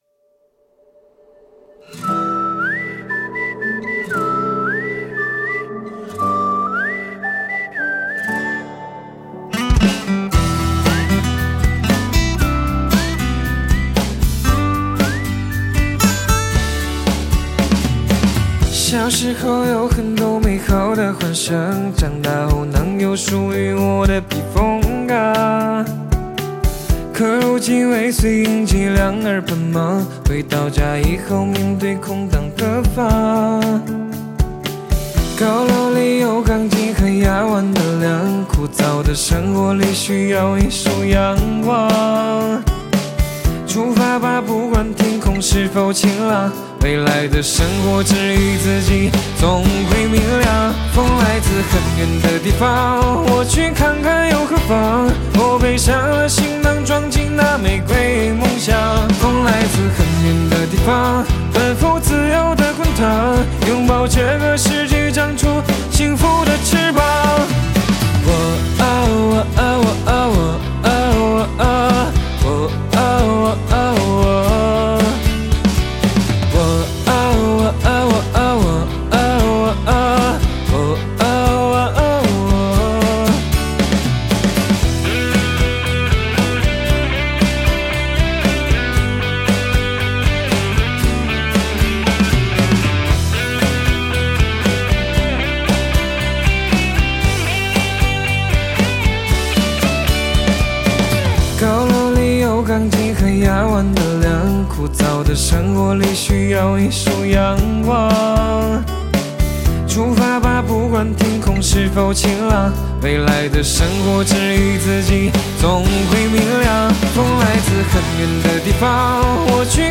吉他Guitar
口哨Whistle